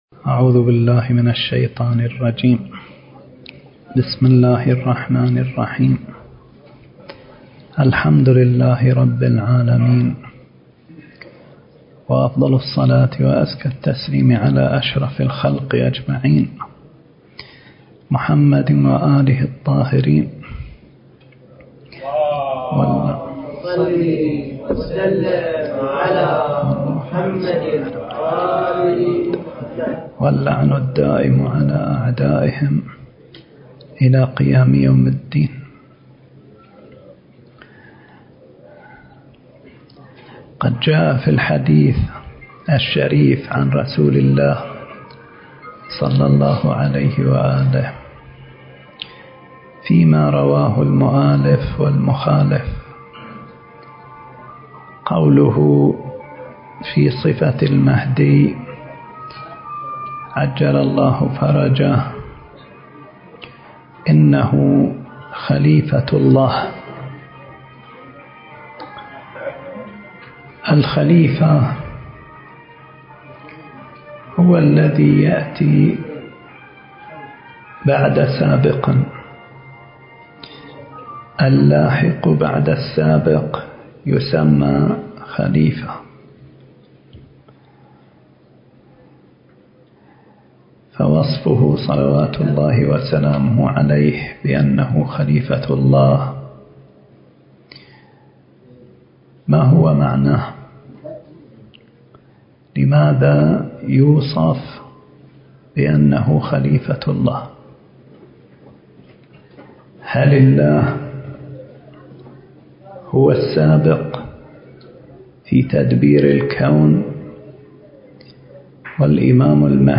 المكان: الحجاز/ الاحساء التاريخ: 2018